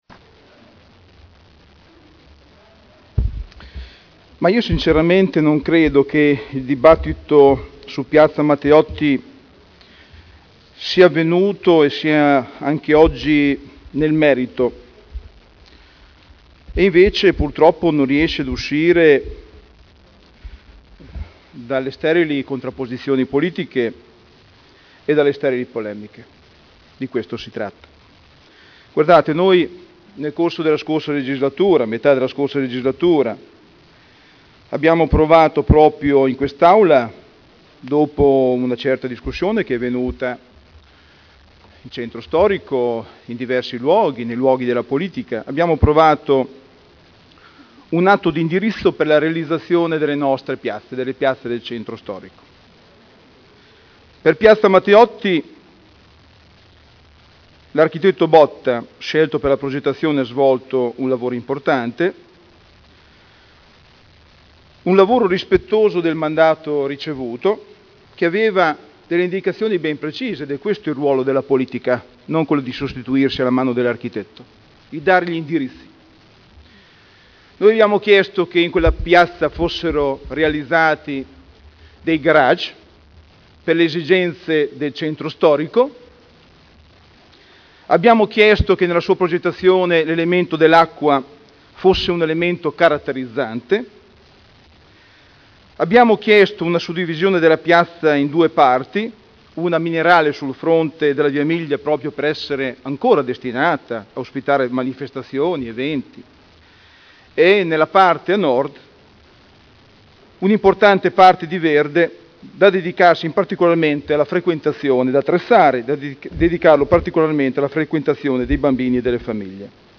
Daniele Sitta — Sito Audio Consiglio Comunale
Seduta del 21/07/2011. Dibattito su delibera: Consultazione popolare ai sensi dell’art. 8 degli istituti di partecipazione del Comune di Modena sul progetto di ristrutturazione di Piazza Matteotti (Conferenza Capigruppo del 27 giugno 2011 e del 4 luglio 2011)